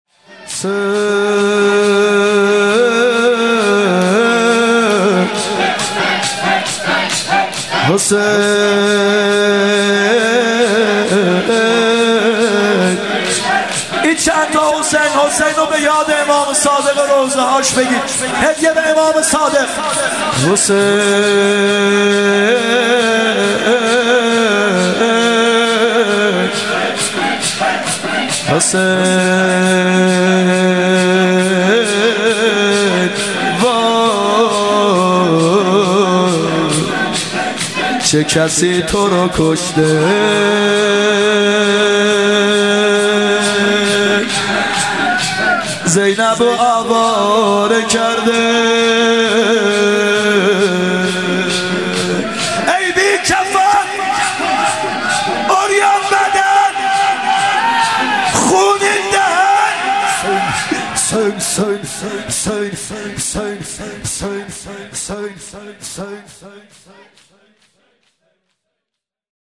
شهادت امام صادق 95 شور
شهادت امام صادق ع (هیات یا مهدی عج)